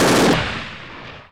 Various MG audio (wav)
machinegun2.wav
machinegun2_178.wav